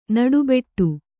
♪ naḍu beṭṭu